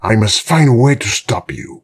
brickmove02.ogg